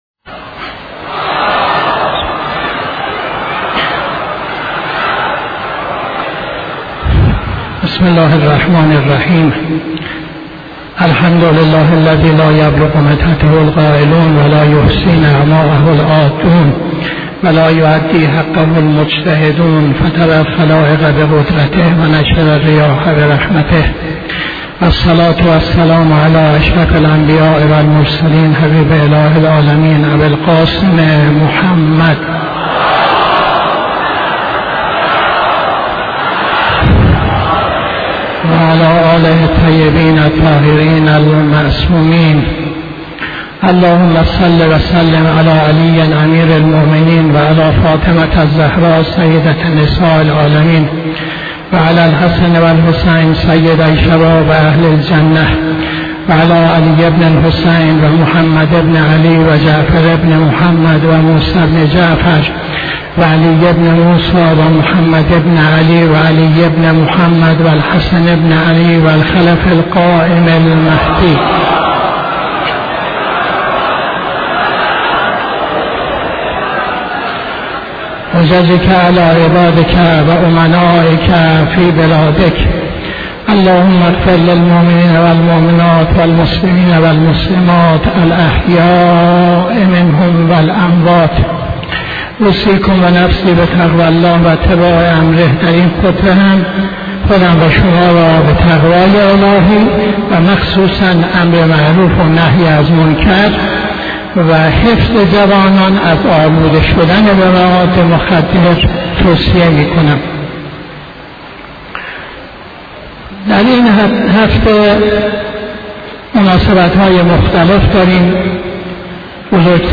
خطبه دوم نماز جمعه 07-06-82